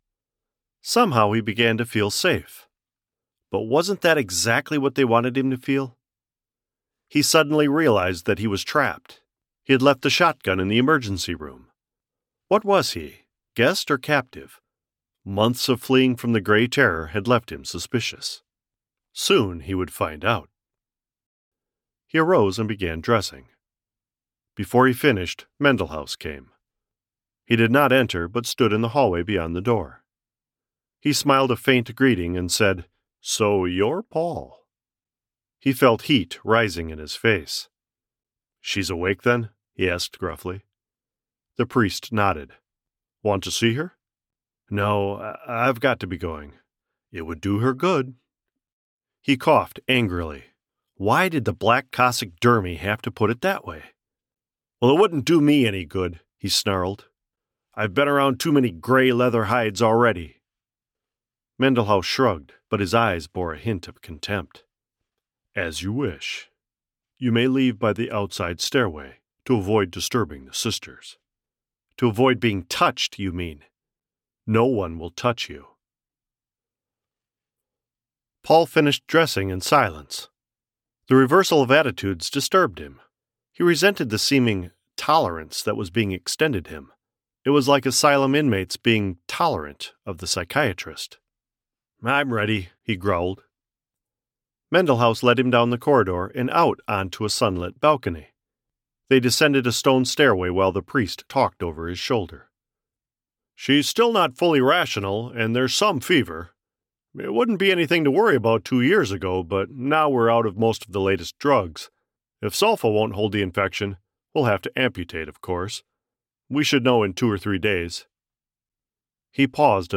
Audiobook Excerpt: Dark Benediction by Walter M. Miller, Jr
North American (General); North American (Midwest)
Middle Aged